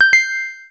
coin
Coin sound, can be a fun entry chime!